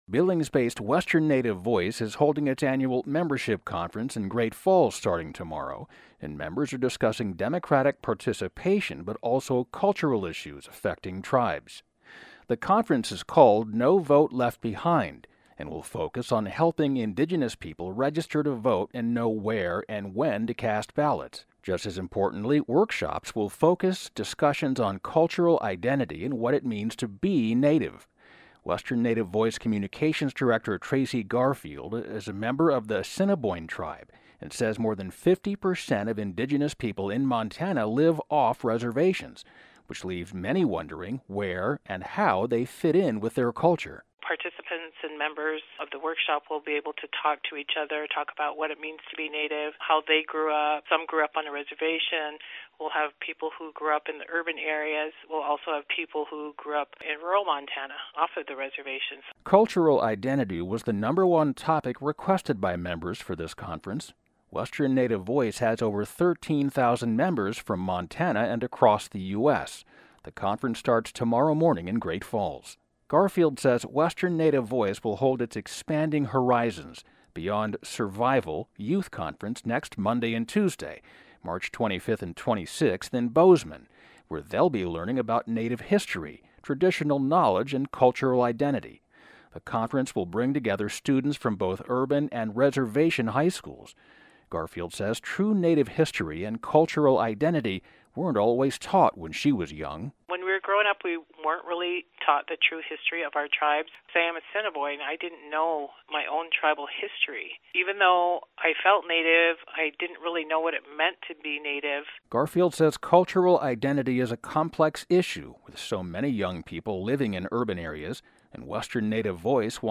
Western Native Voice Assiniboine (ah SIN uh boyne)